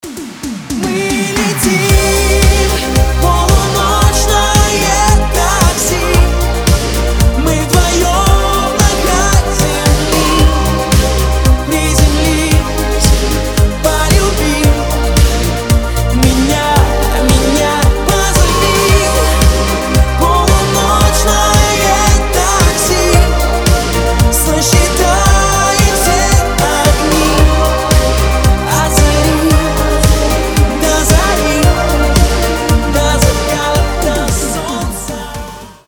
• Качество: 320, Stereo
поп
мужской вокал
громкие
Synth Pop